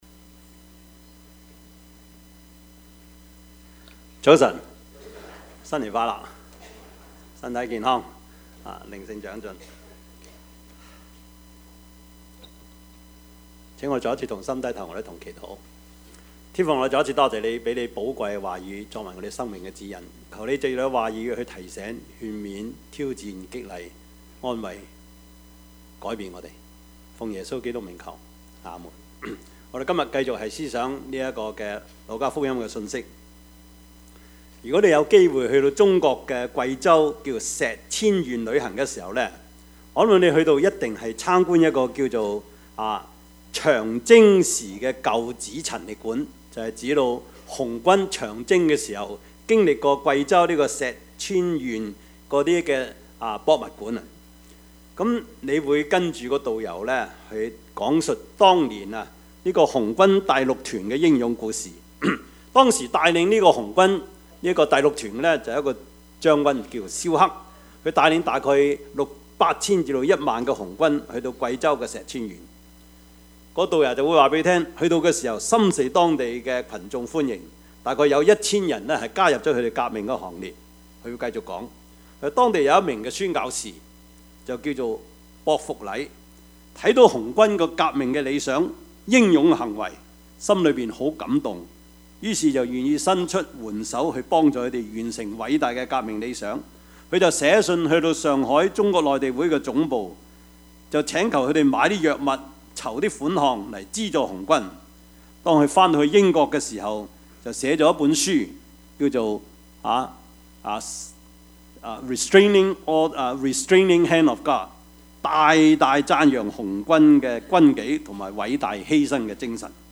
Passage: 路加福音 14:15-24 Service Type: 主日崇拜
Topics: 主日證道 « 事就這樣成了 一個父親的叮嚀 »